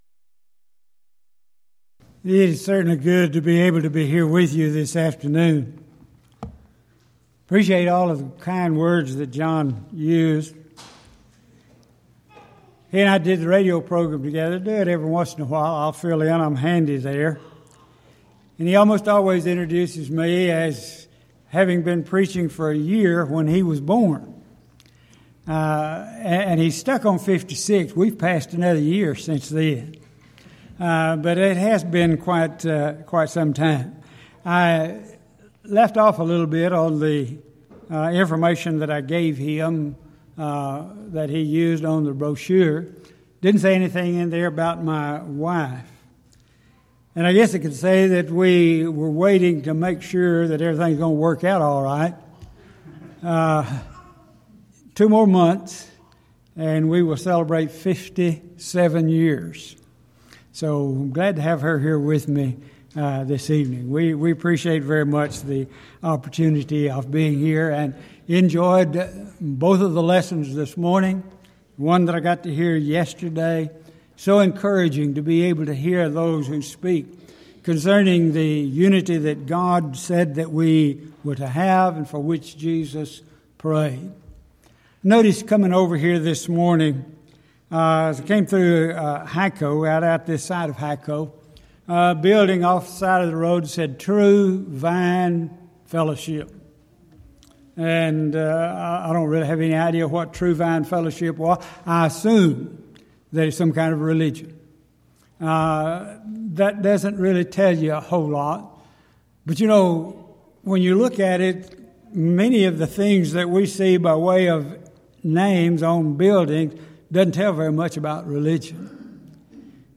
Event: 4th Annual Back to the Bible Lectures Theme/Title: The I Am's of Jesus
lecture